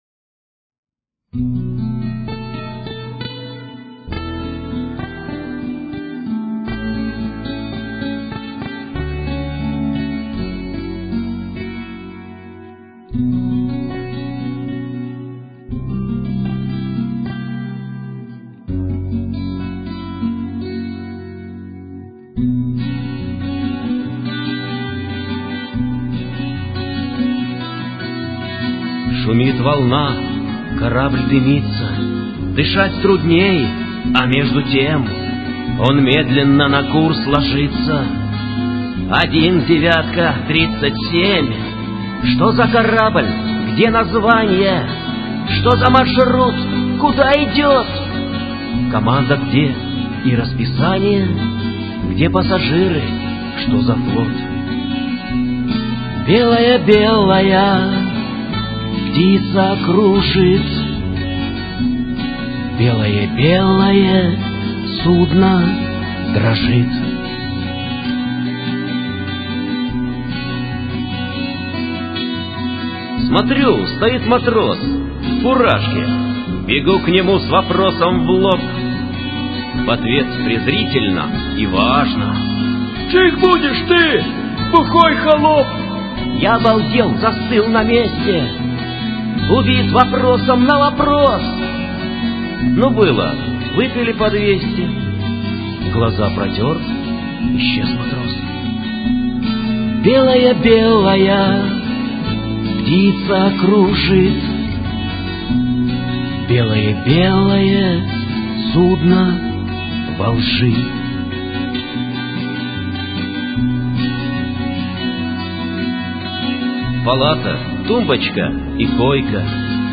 ~ Песни под гитару ~